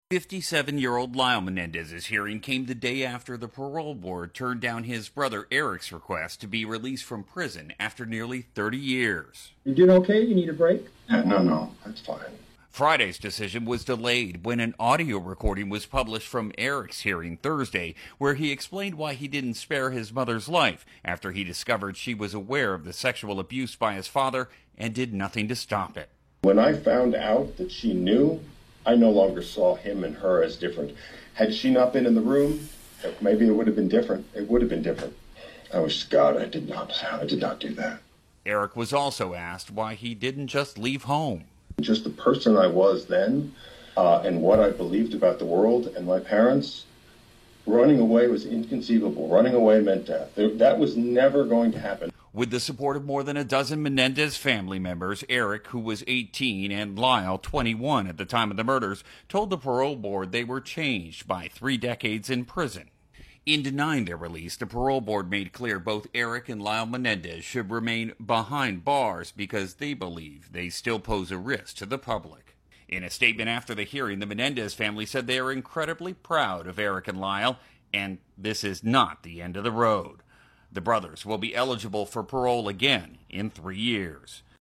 In audio from his hearing, you can hear Erik explain why he didn't spare his mother's life.